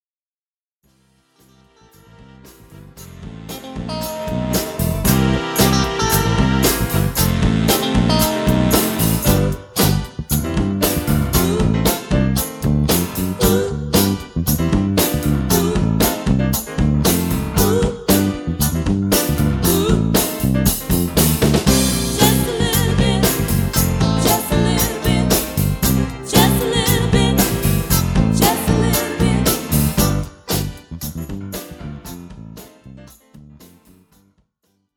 This is an instrumental backing track cover.
• With Backing Vocals
• With Fade